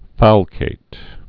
(fălkāt) also fal·cat·ed (-kātĭd)